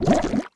eat_potion.wav